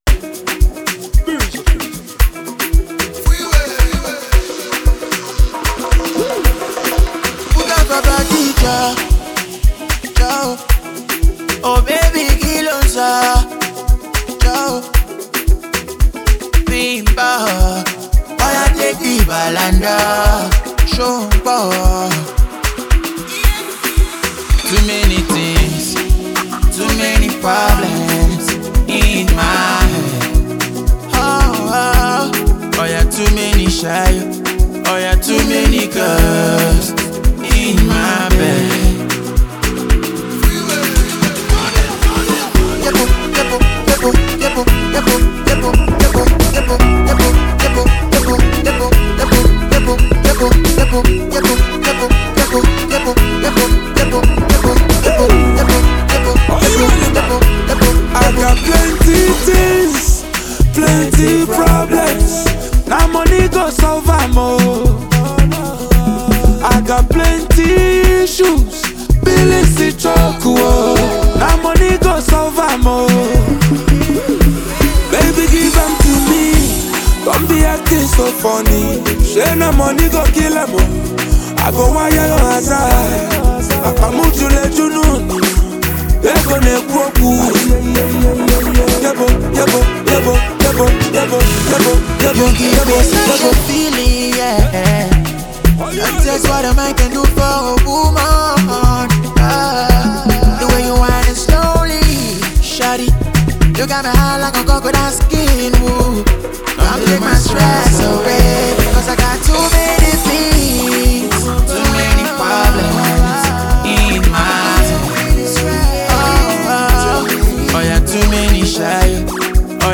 Renowned Nigerian Afrobeats talent and performer
The music scene is excited to embrace this energetic release